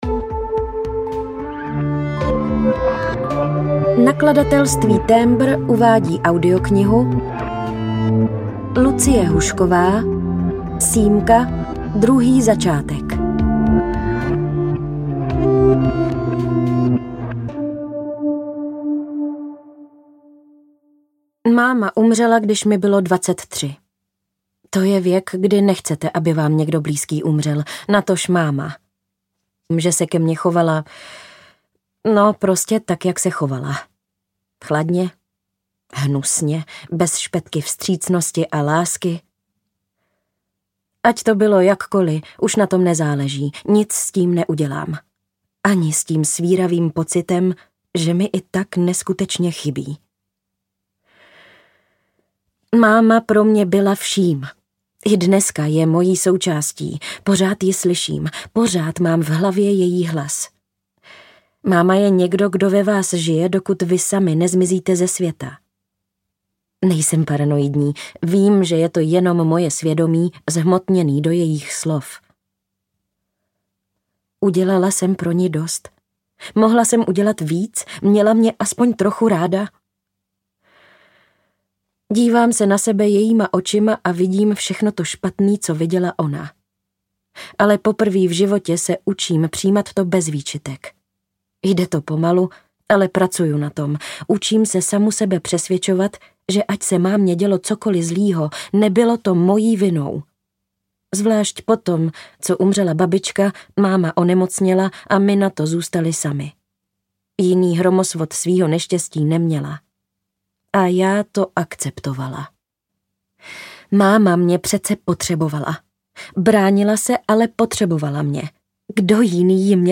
Druhý začátek audiokniha
Ukázka z knihy